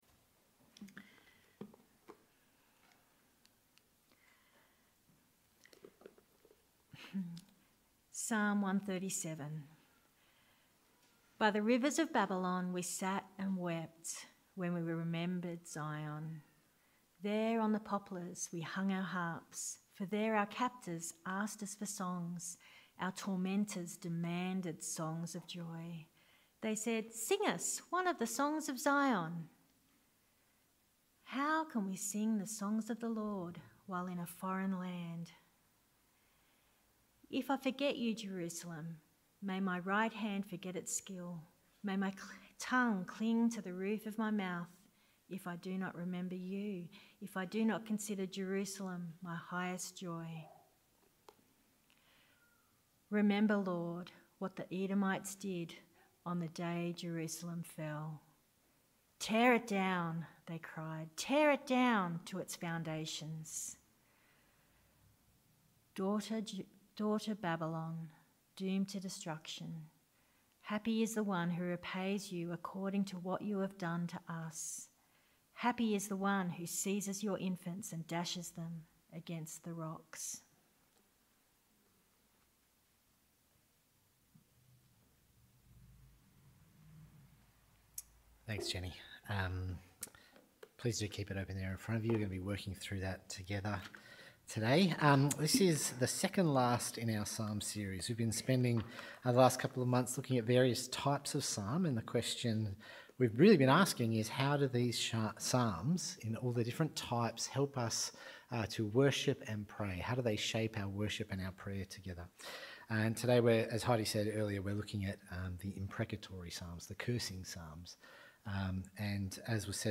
Warning: The following sermon contains some difficult themes of violence, abuse, vengeance and infanticide.